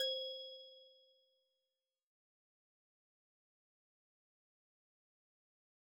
cursor_style_1.wav